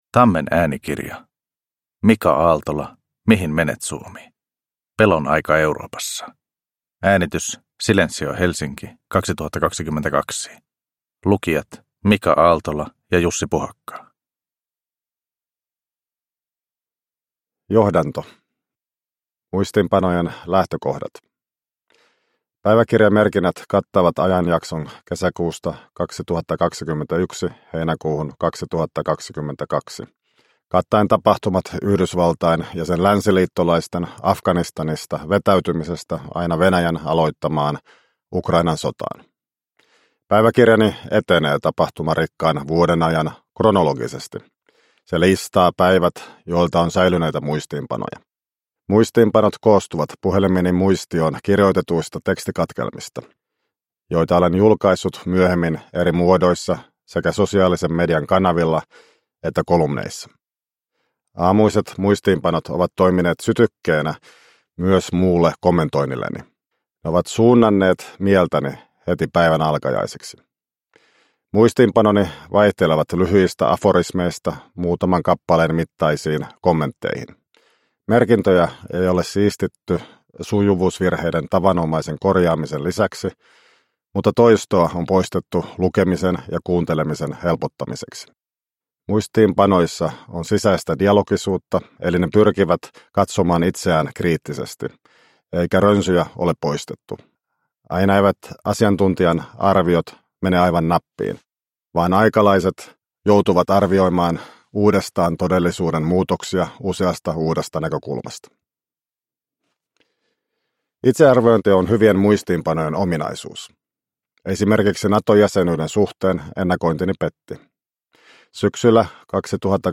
Mihin menet Suomi? – Ljudbok – Laddas ner